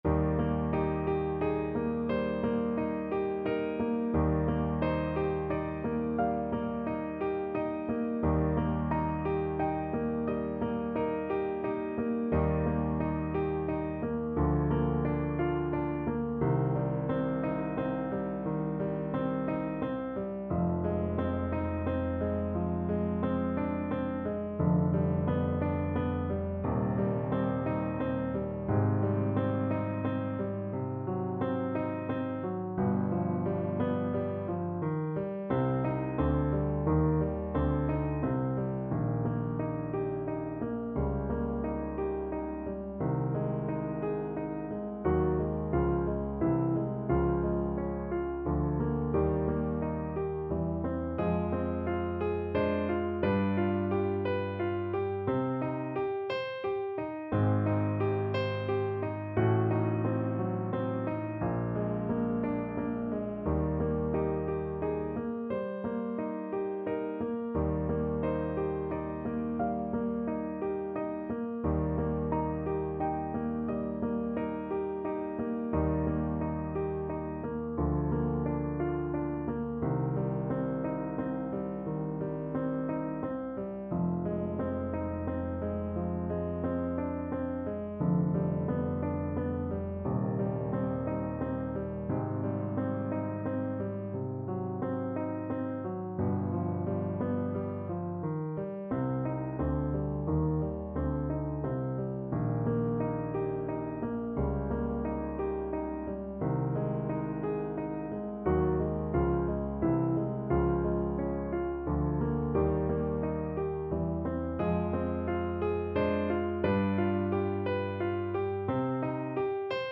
~ = 88 Andante
6/4 (View more 6/4 Music)
Classical (View more Classical Voice Music)